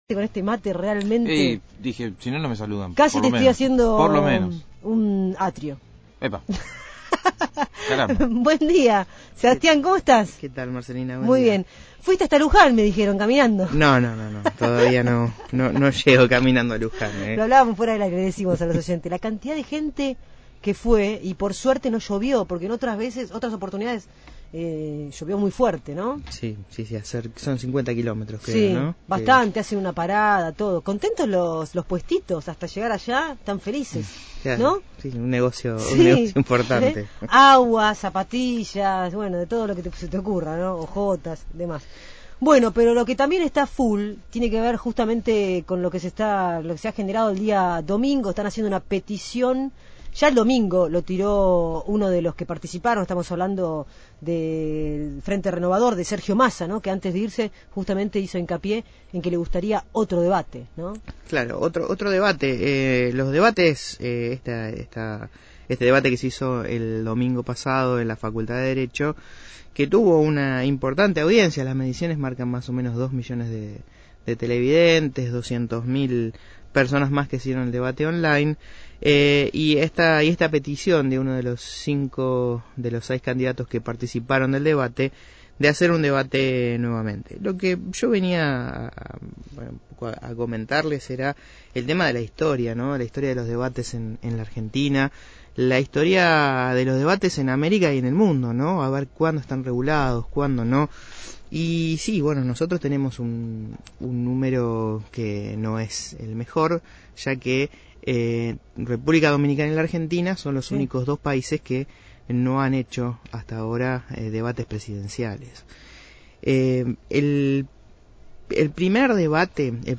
visitó el estudio de Radiópolis Ciudad Invadida y analizó las repercusiones del debate que protagonizaron cinco de los seis candidatos a presidente de la Nación el domingo por la noche.